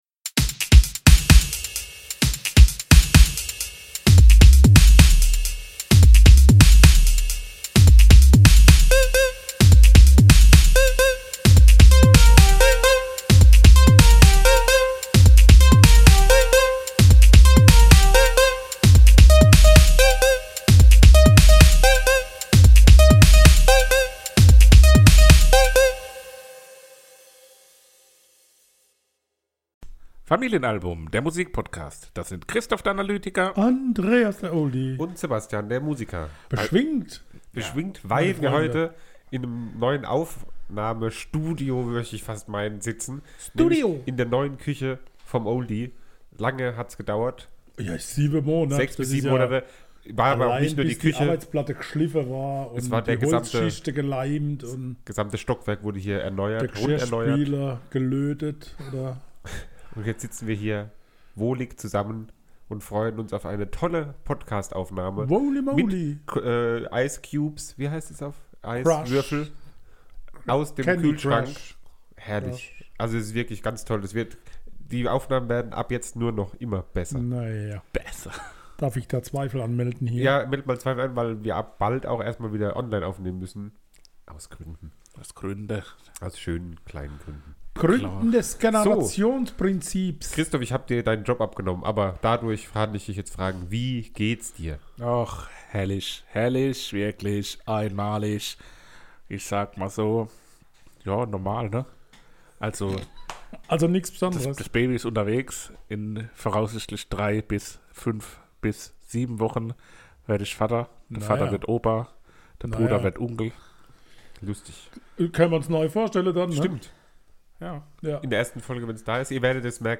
Oktober 2022 Nächste Episode download Beschreibung Kapitel Teilen Abonnieren In Folge 56, der ersten Folge die in der neuen Oldie-Küche aufgenommen wurde, klären wir die Frage an wen wir denken wenn wir die Namen Pamela, Jenny und Scarlett hören.